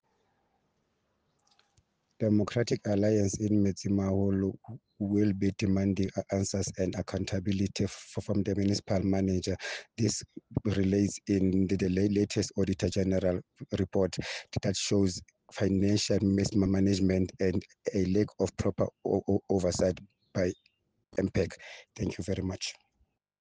Sesotho soundbites by Cllr Thulani Mbana and